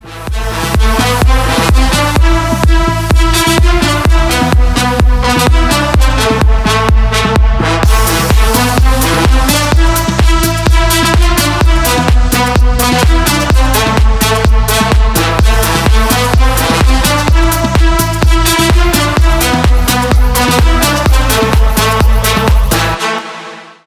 без слов
edm